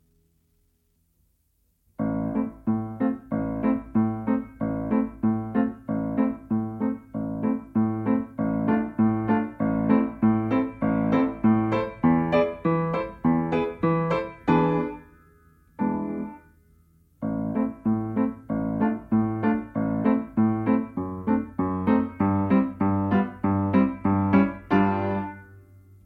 Nagranie bez metronomu, uwzględnia rubata.
Allegro moderato: 90 bmp
Nagranie dokonane na pianinie Yamaha P2, strój 440Hz